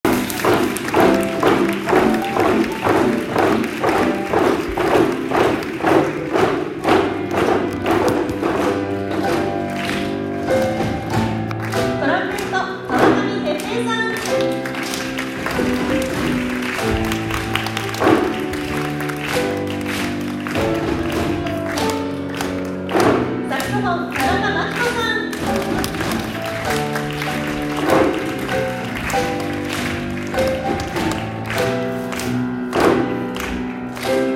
10月31日(金)に本校体育館で日本テレビ小鳩文化事業団によるスクールコンサートが開催されました。
コンサートでは、児童生徒のピアノとカップスに合わせて5名の演奏者が入場されました。